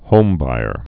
(hōmbīər)